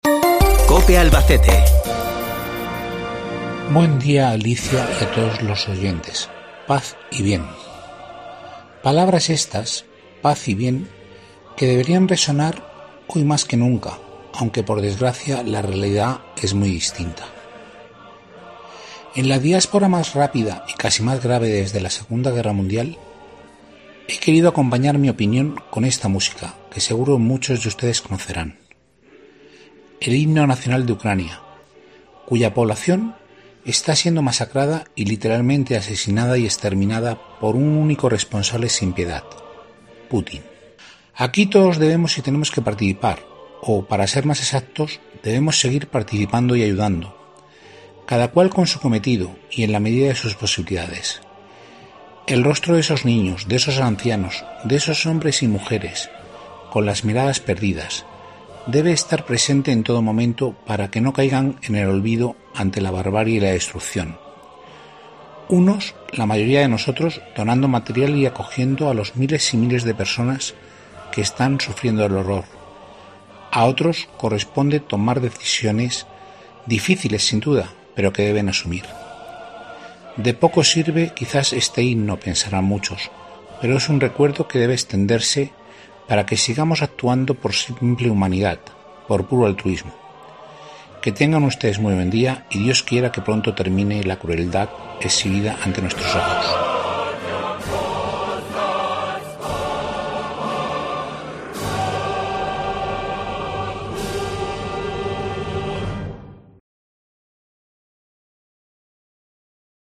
se hace acompañar en su opinión por el himno de Ucrania